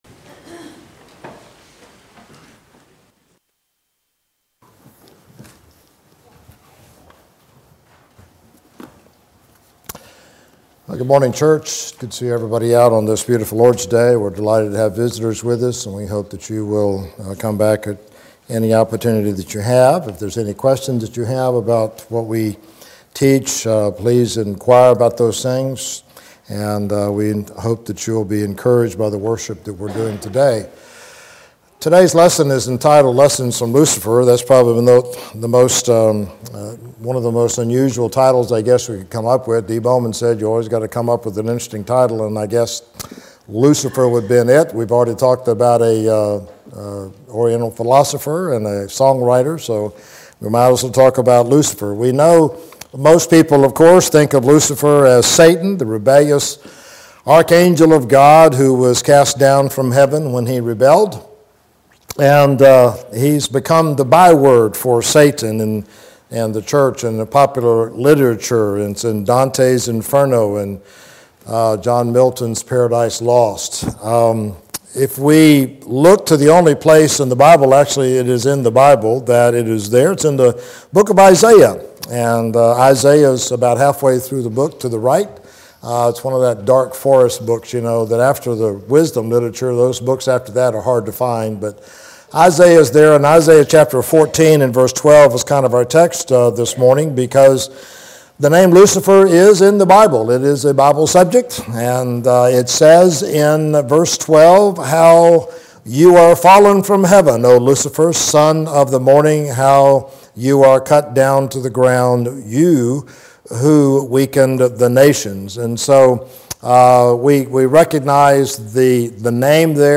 Sermon – Lessons of Lucifer